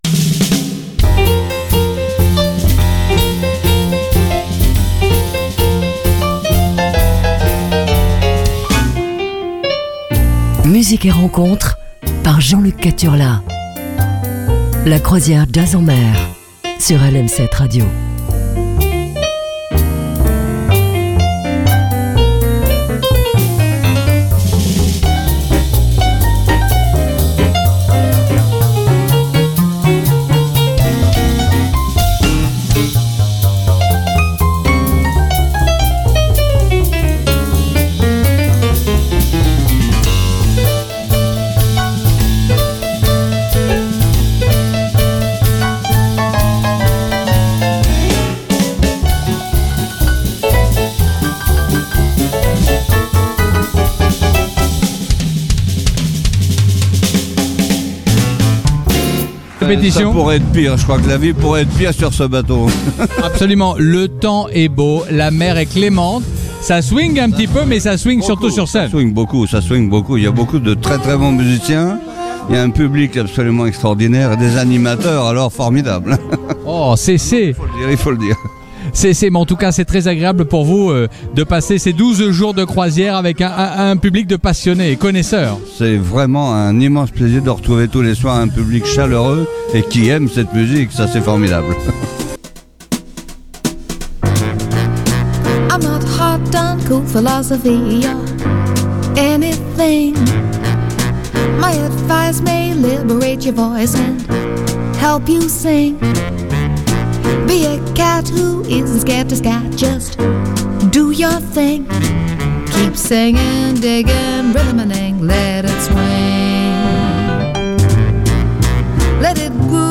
passer une demi-heure sur des rythmes jazzy